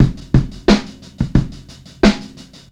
Bronxbeat1 88bpm.wav